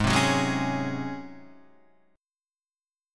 G# 11th